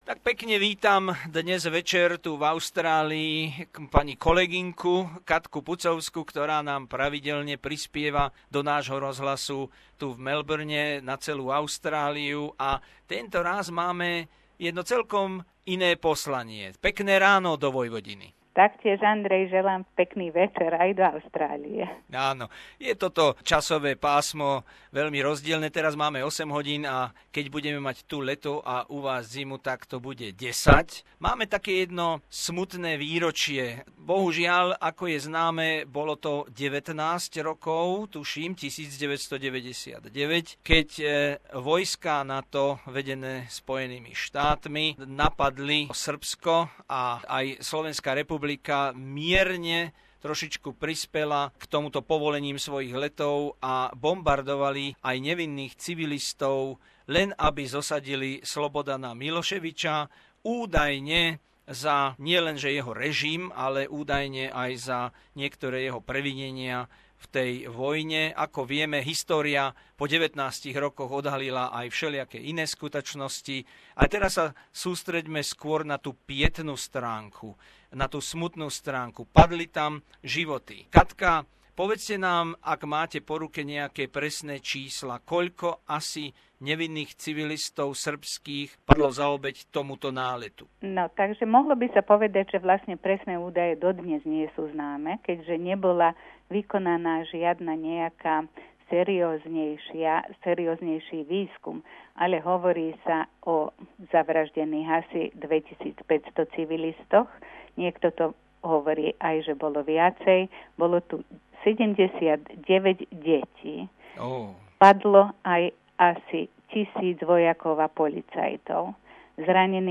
Čas pre nášho hosťa.